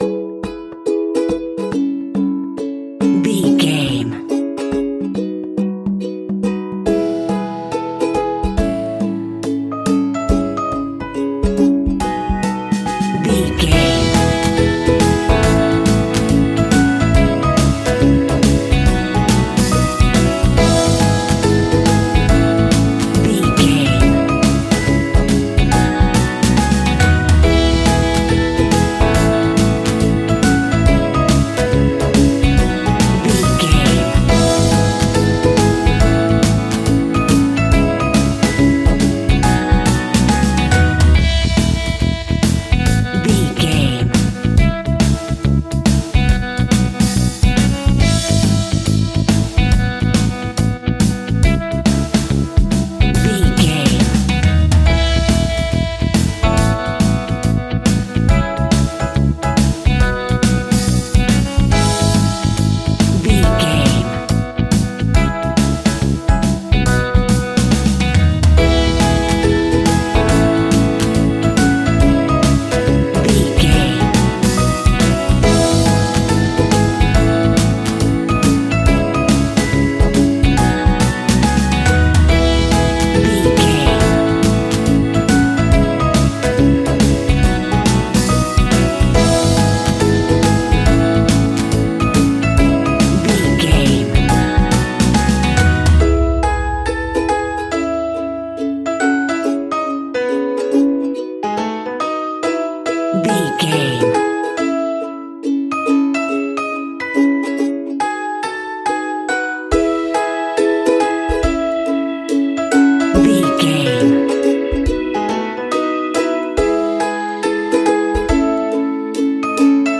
Pop Music on the Ukulele.
Ionian/Major
happy
peppy
upbeat
bouncy